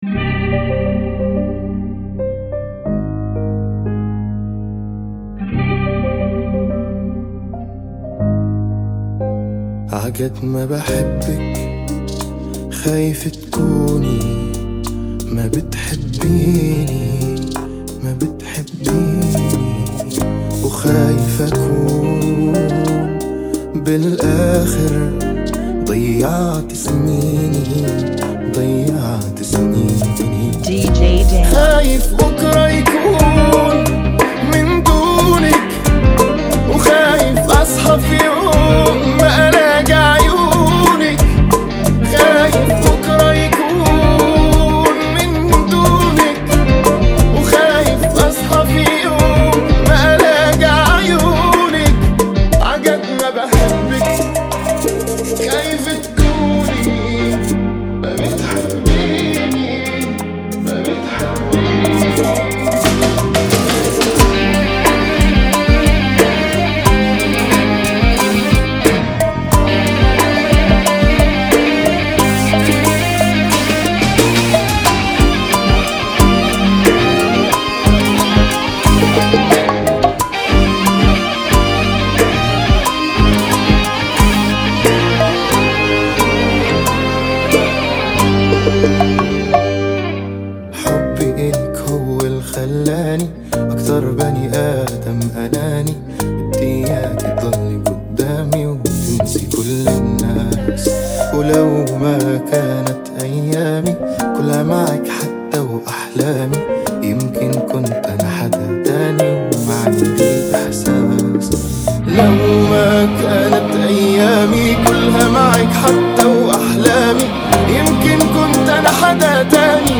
90 BPM
Genre: Bachata Remix